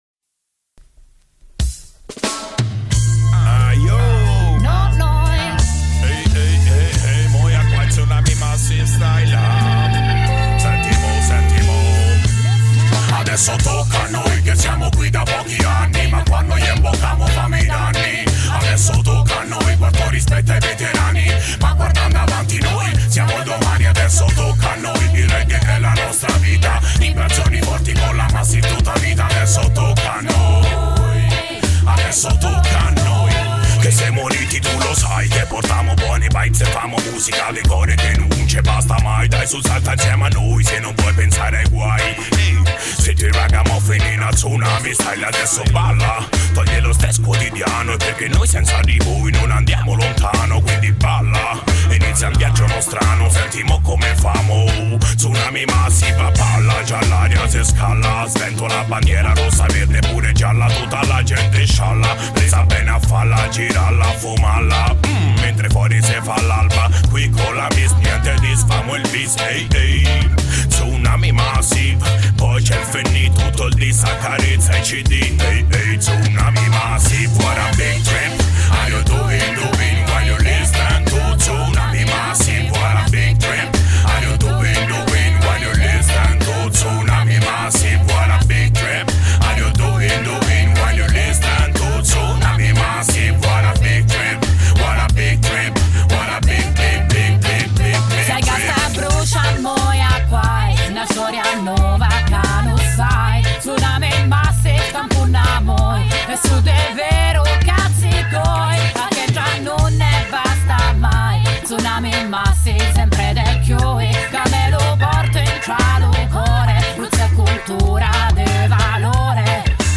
Artista-a-la-Vista-Intervista-Tsunami-Massive.mp3